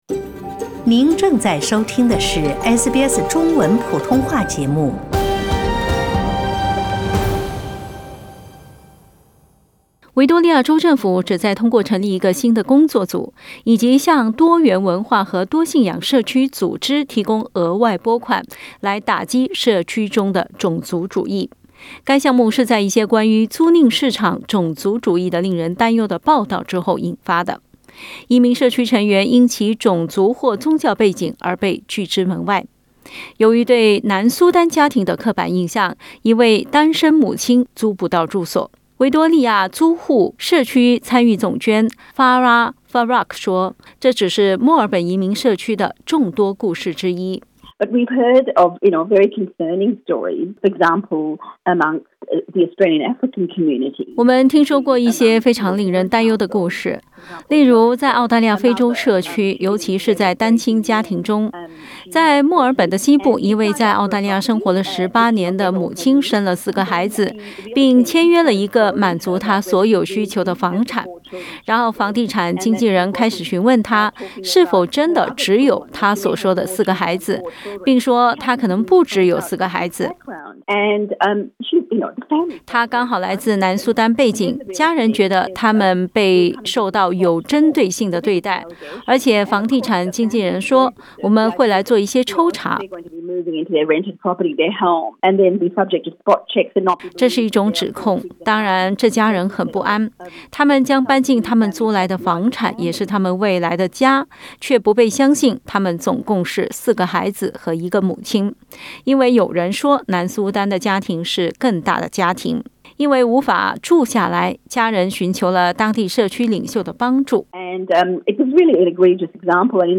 這些事例促使維多利亞州政府成立了“反種族主義工作組”，併提供額外資金在噹地開展草根運動以阻止社區中出現這樣的事情。 作為該項目的一部分，42 個社區組織將分享約 380 萬澳元，以促進全州的跨文化理解。 （請聽報道） 澳大利亞人必鬚與他人保持至少1.5米的社交距離，請查看您所在州或領地的最新社交限制措施。